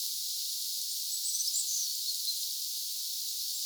hippiäisen rautiaislintumatkinta?
Siis osa kuin jonkin rautiaislinnun säkeestä?
minka_linnun_sae_tai_ehka_mieluummin_osa_sakeesta_onko_hippiaisen_rautiaislintumatkinta.mp3